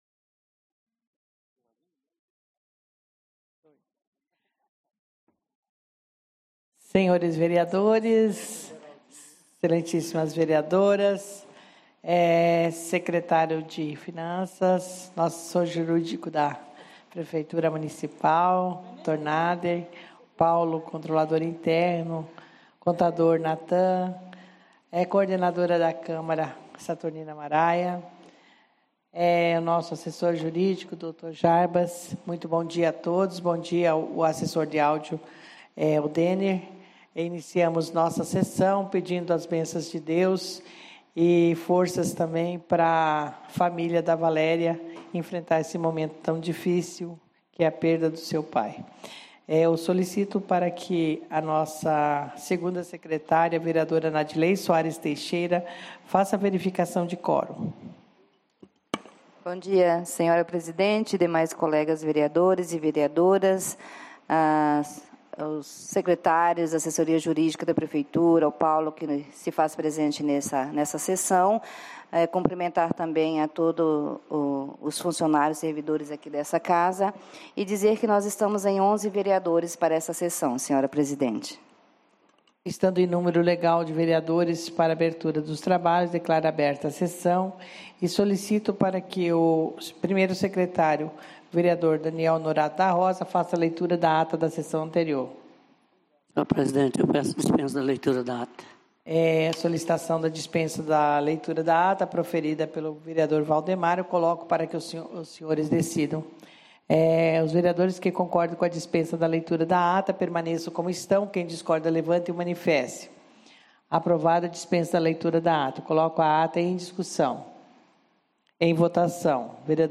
09/11/2015 - Sessão Ordinária